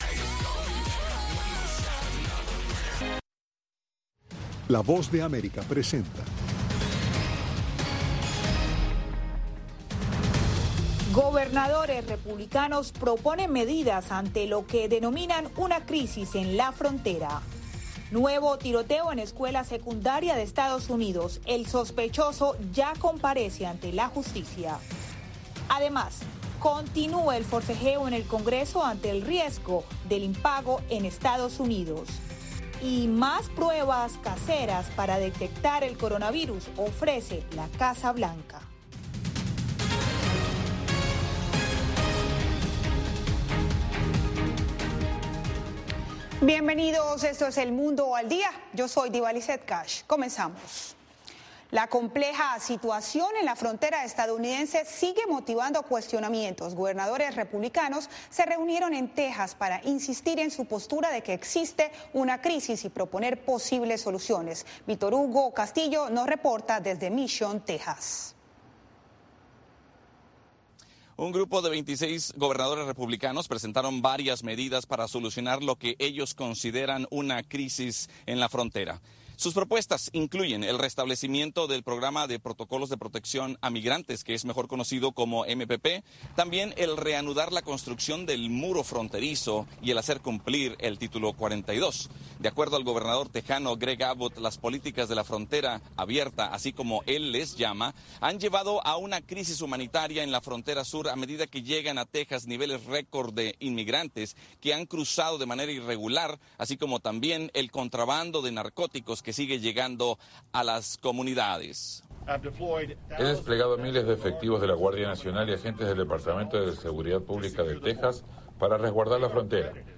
Un noticiero con información diaria de Estados Unidos y el mundo.